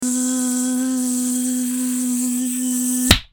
bees.mp3